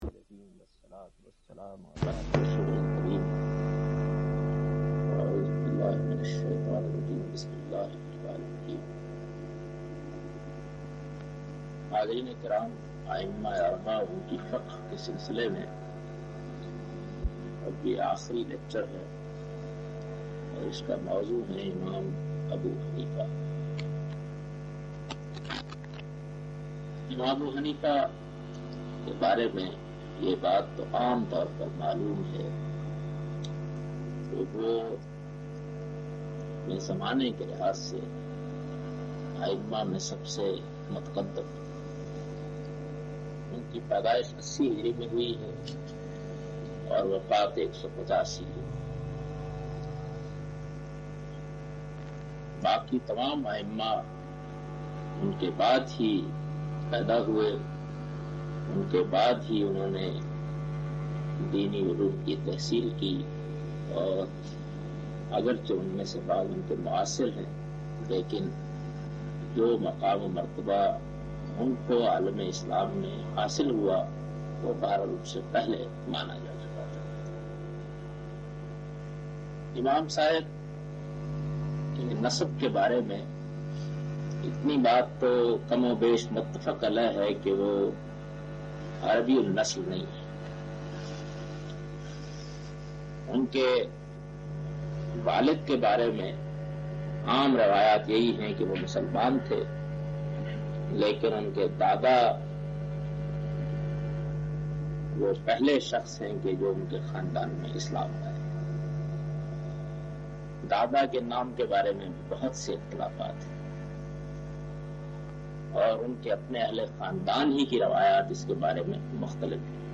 In this video Javed Ahmad Ghamidi speaks about Fiqh of Imam Abu Hanifa.